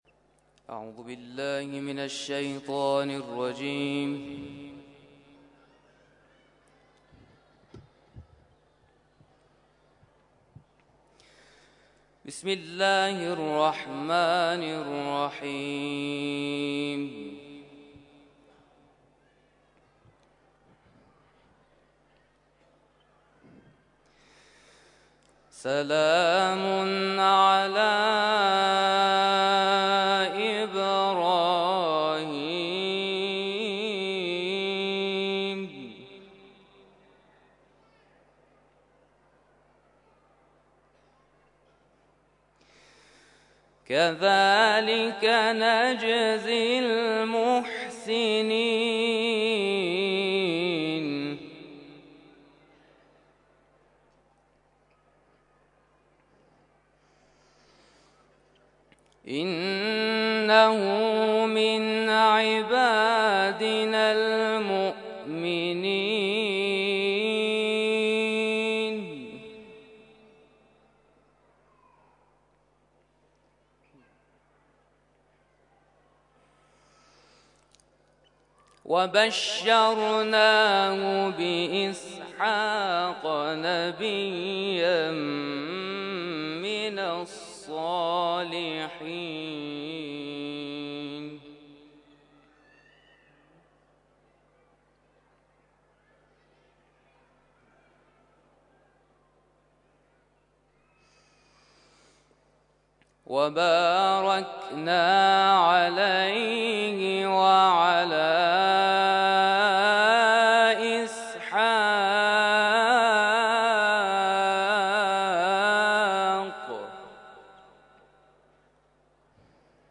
محافل و مراسم قرآنی
تلاوت قرآن کریم